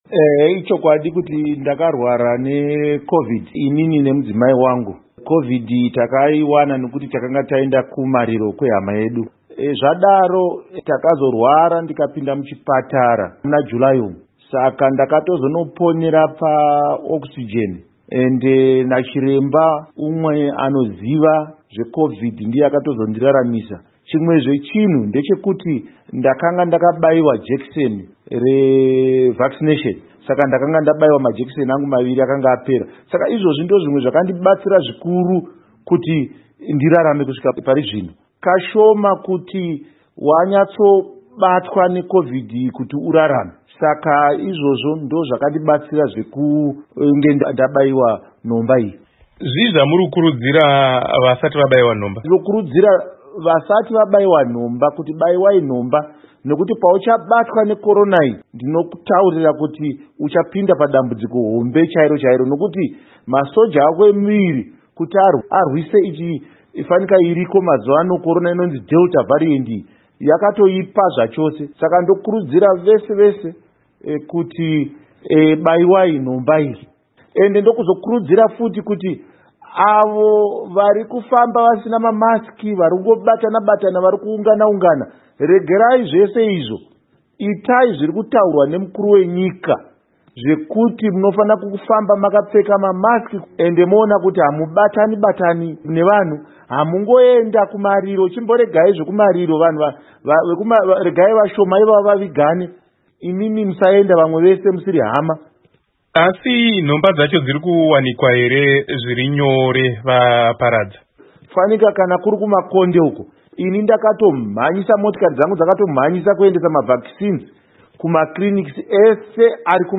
Hurukuro naVaKindness Paradza